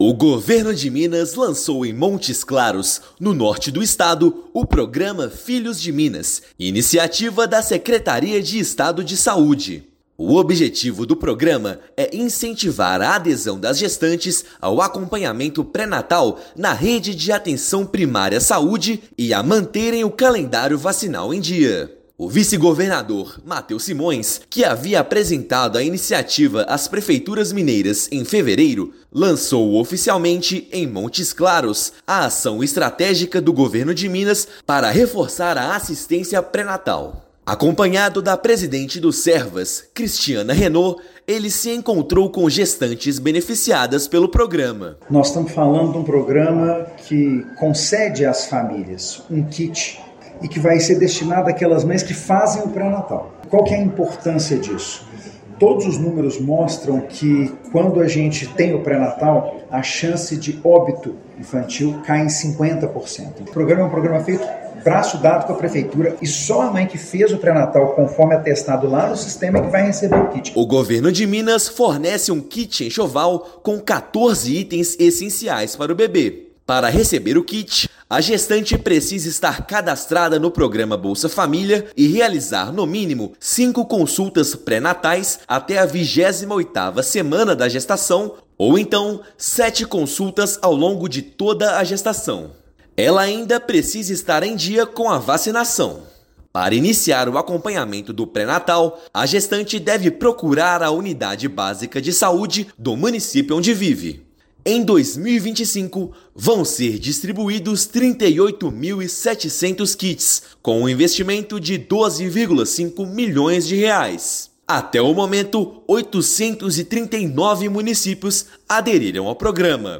Ações do Filhos de Minas buscam intensificar cuidados, ampliar acesso ao pré-natal e garantir suporte às famílias mais vulneráveis com a entrega de kits enxovais. Ouça matéria de rádio.